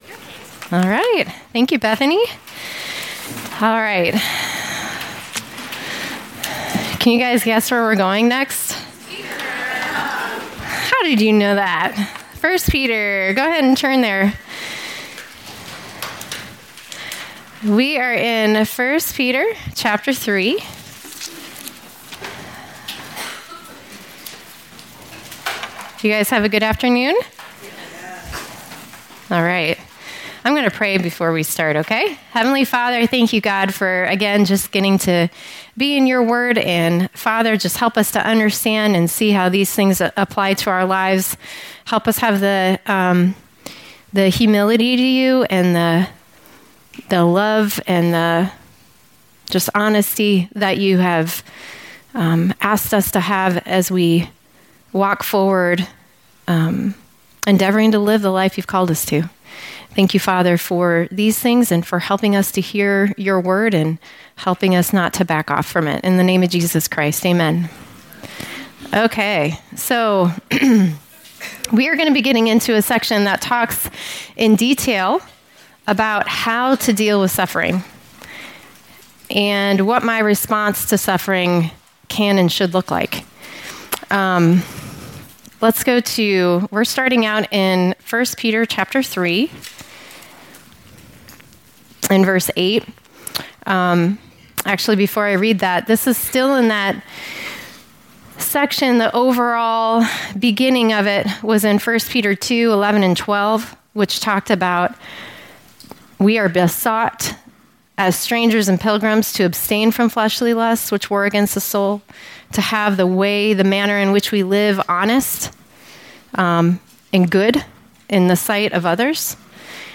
Women’s Weekend 2023